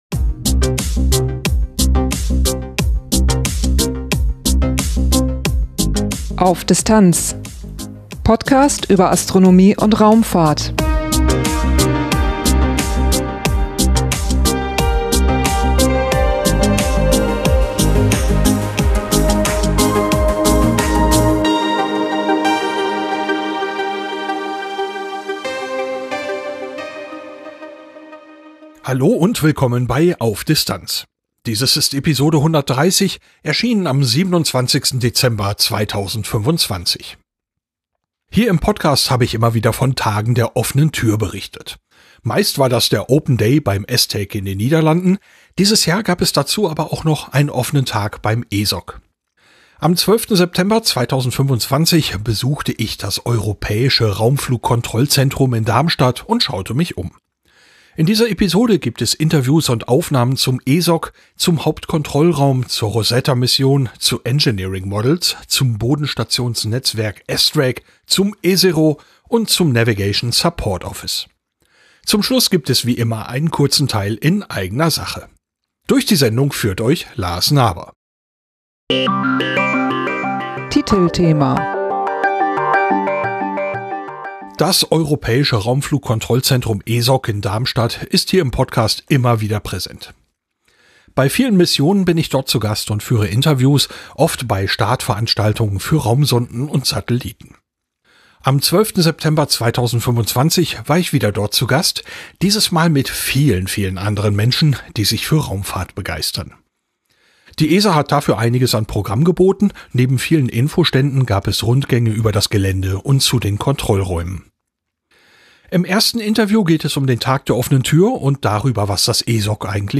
Am 12. September 2025 besuchte ich das europäische Raumflugkontrollzentrum ESOC der ESA in Darmstadt und schaute mich um. In dieser Episode gibt es Interviews und Aufnahmen zum ESOC, zum Hauptkontrollraum, zur Rosetta-Mission, zu Engineering models, zum Bodenstationsnetzwerk ESTRACK, zum ESERO und zum Navigation Support Office.